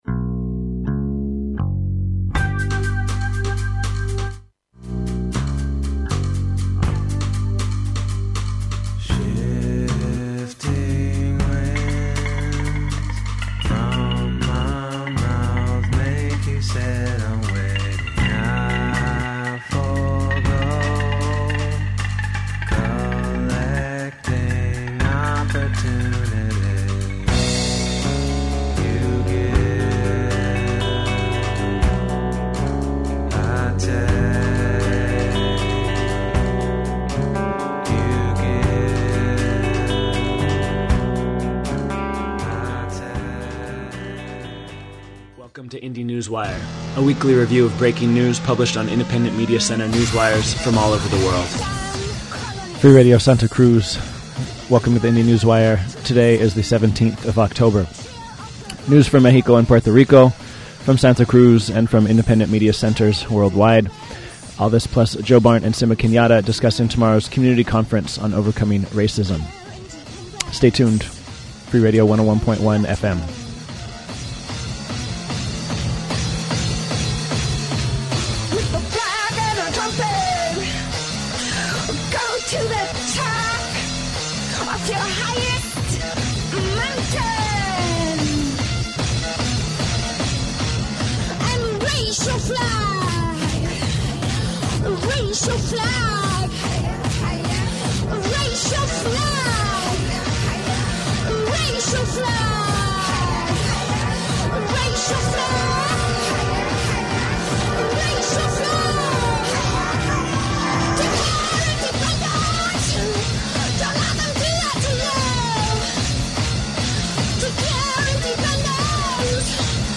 The indynewswire airs on Freak Radio Santa Cruz every Friday from 10 AM - 12 Noon. 1 hr. 23 min. mono MP3 format.